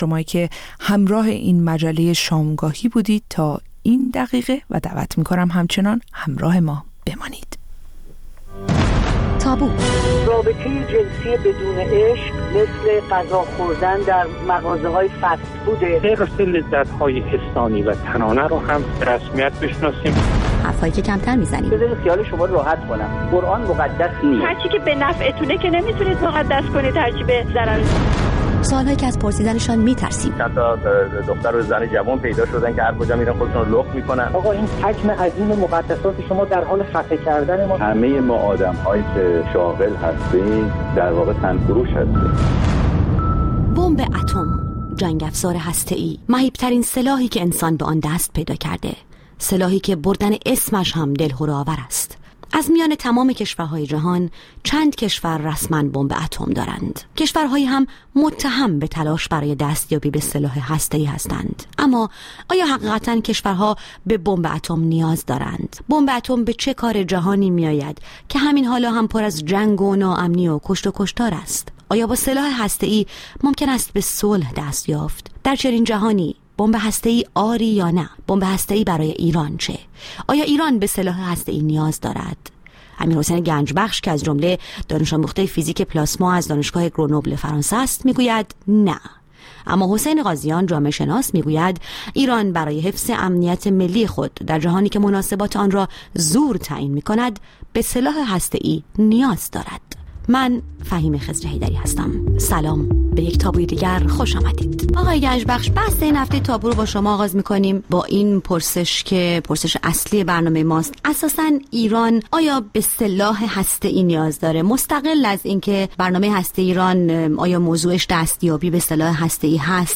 با دو‌ مهمانِ برنامه درباره‌ی موضوعاتی که اغلب کمتر درباره‌شان بحث و گفت‌وگو کرده‌ایم به مناظره می‌نشیند. موضوعاتی که کمتر از آن سخن می‌گوییم یا گاه حتی ممکن است از طرح کردن‌شان هراس داشته باشیم.